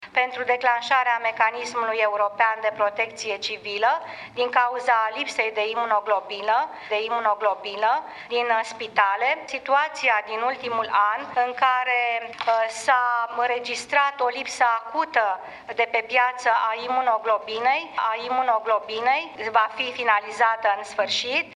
Greșeală de pronunție a premierului Dăncilă: „imunoglobină” în loc de imunoglobulină | AUDIO
Premierul Viorica Dăncilă a declarat, în şedinţa de Guvern de miercuri, că prin declanşarea Mecanismului European de Protecţie Civilă va fi rezolvată pe termen scurt criza de ”imunoglobină”.
Premierul a folosit greşit termenul de şase ori, spunând imunoglobină, în loc de imunoglobulină.